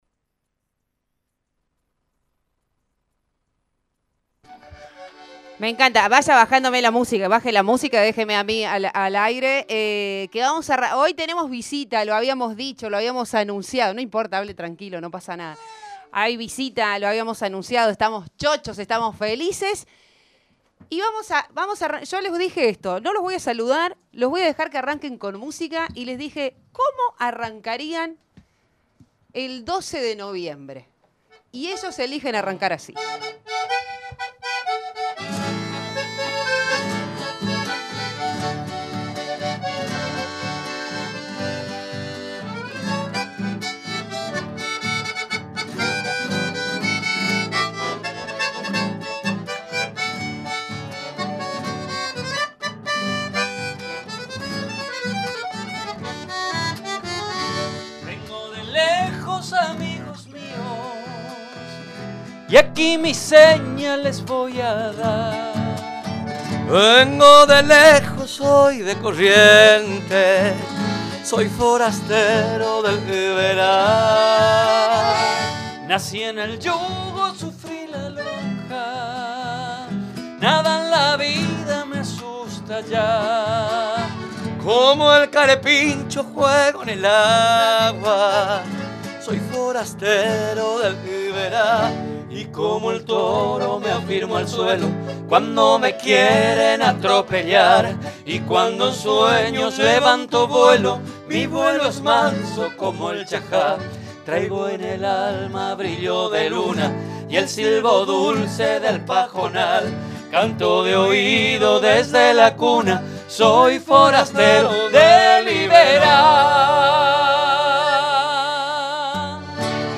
Los Alonsitos visitaron los estudios de la radio en el marco de una invitación para el Anfi Vivo, este evento folklórico que tendrá lugar el próximo 12 de noviembre.